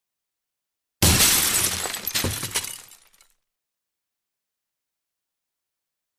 Large Shatter; Safety Glass; Large Pane Of Glass Being Broken; Lots Of Falling Debris, Close Perspective.